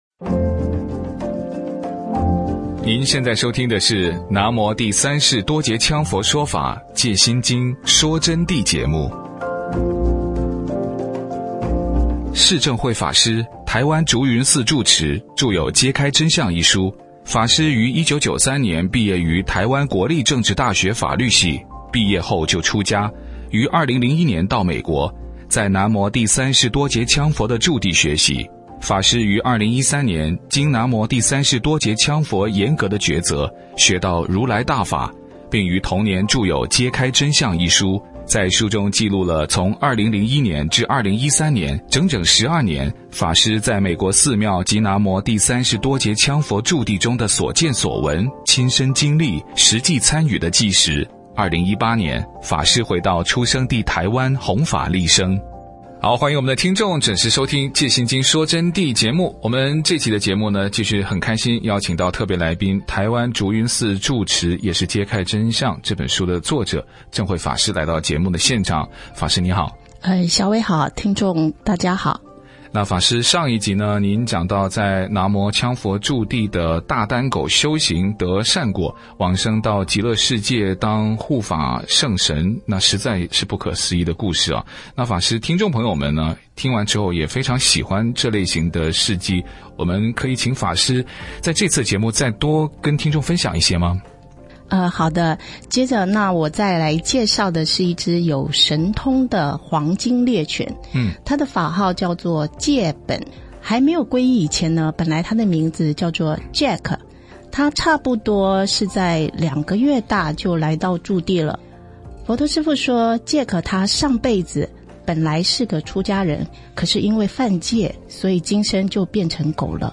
佛弟子访谈（四十二）南无羌佛驻地一只有神通的狗狗与吃到剧毒草差点见阎王的真实事件